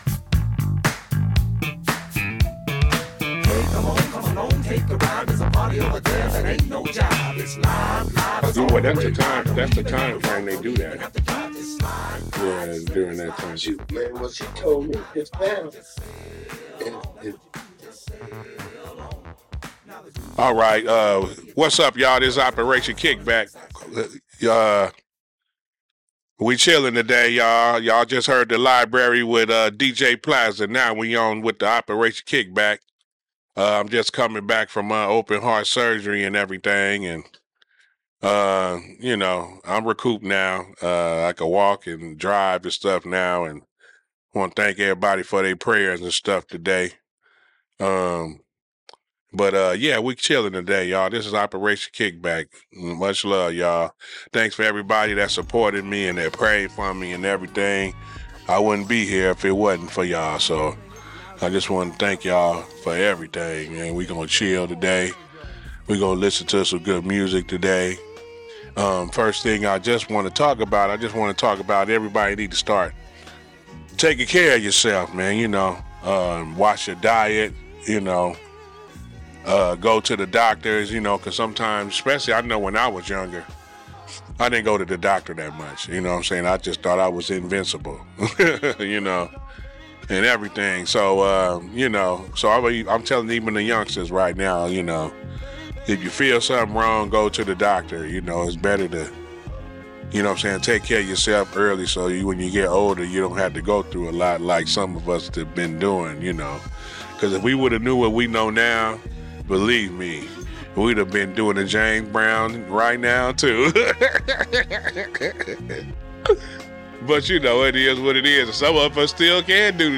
This Episode of Operation KickBack aired live on CityHeART Radio on Tuesday 3.17.26 at 1pm.